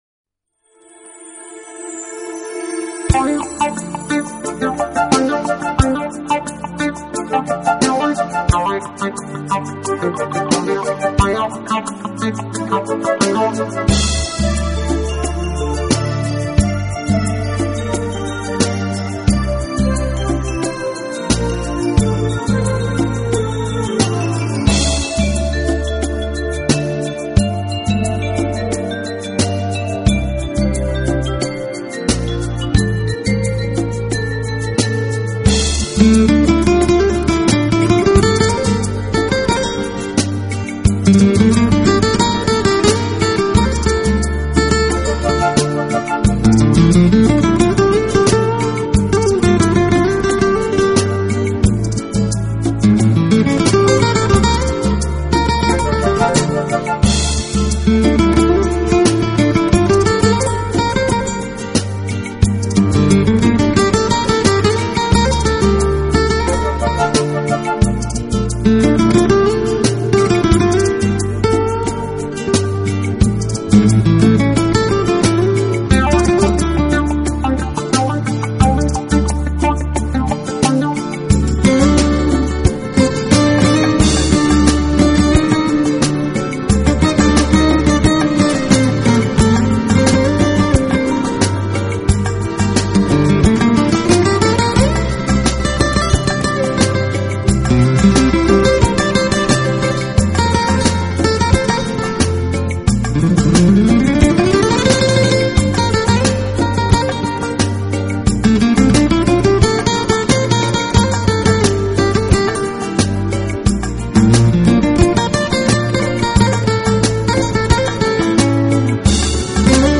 【吉他专辑】
整张乐曲中除了本身吉它的演奏以外，还能够听见小段的电子琴，手风琴与其他乐器
指间轮转流畅，充满华美、热情、宁静的情感，正如明亮和煦的